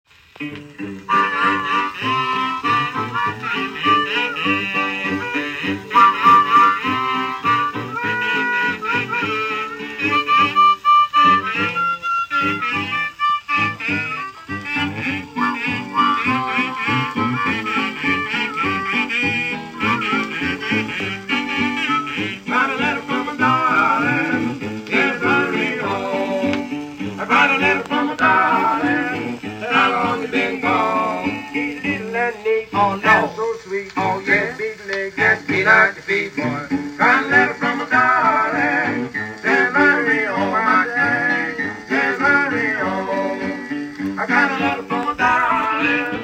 Side B V+. Both sides play like an E-